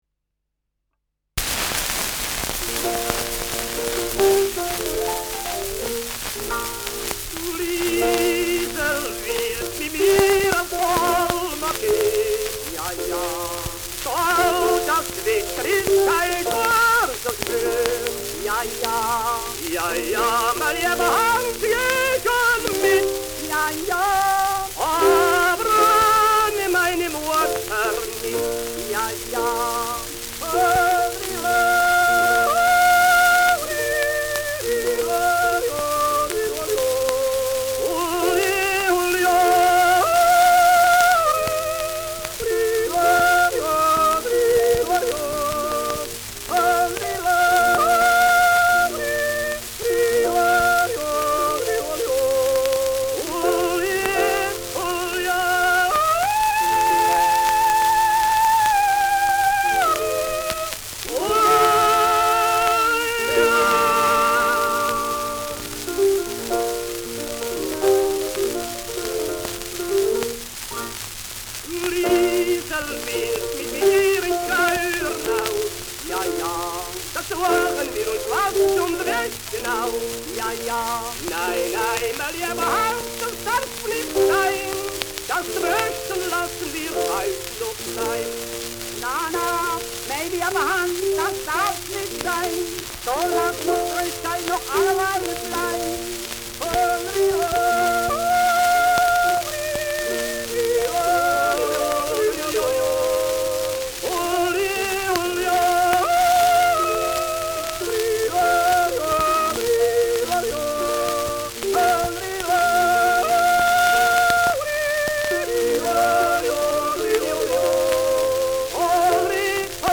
Schellackplatte
ausgeprägtes Rauschen : Knistern